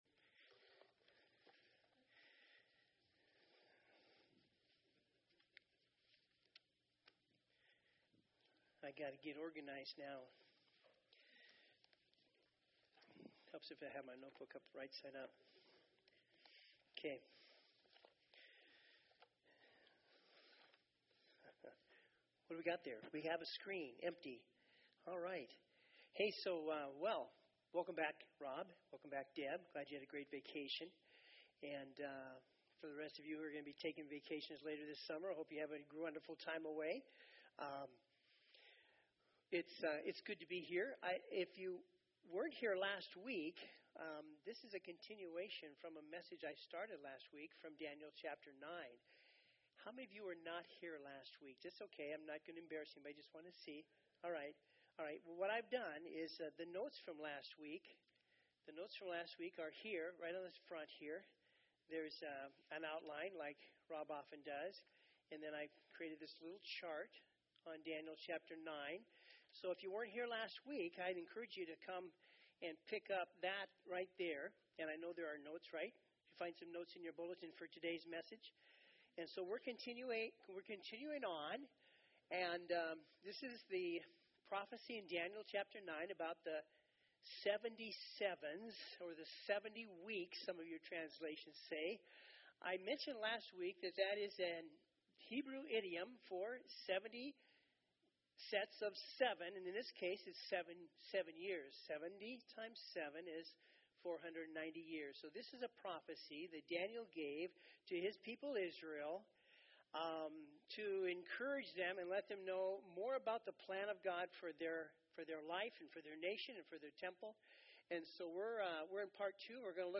SERMONS The Last Seventy Sevens of Daniel